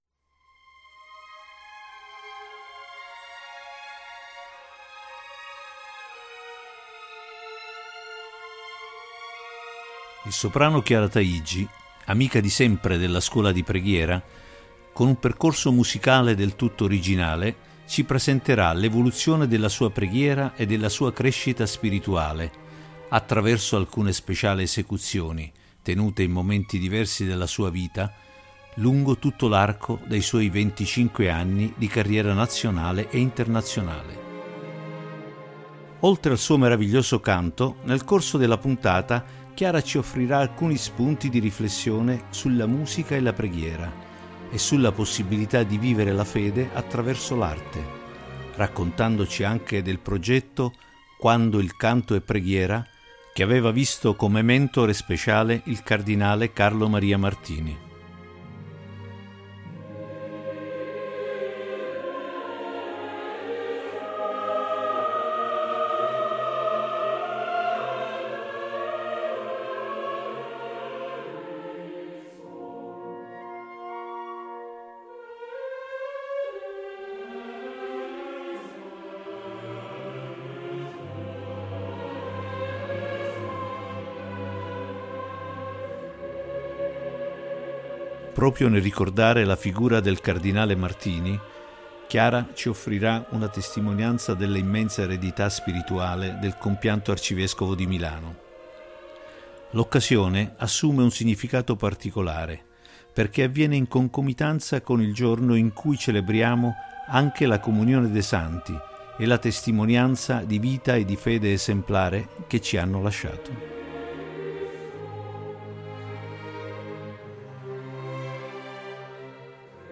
Il soprano Chiara Taigi, amica di sempre della Scuola di Preghiera, ci accompagna in un percorso musicale del tutto originale, presentandoci l’evoluzione della sua preghiera e crescita spirituale attraverso alcune speciali esecuzioni, tenute in momenti diversi della sua vita, che coprono tutto l’arco dei suoi 25 anni di carriera nazionale e internazionale.